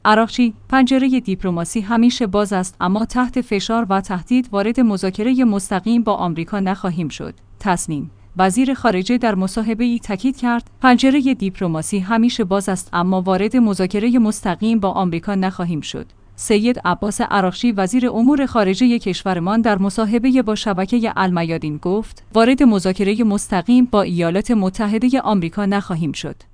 تسنیم/ وزیر خارجه در مصاحبه‌ای تاکید کرد: پنجره دیپلماسی همیشه باز است اما وارد مذاکره مستقیم با آمریکا نخواهیم شد. سید عباس عراقچی وزیر امور خارجه کشورمان در مصاحبه با شبکه المیادین گفت: وارد مذاکره مستقیم با ایالات متحده آمریکا نخواهیم شد.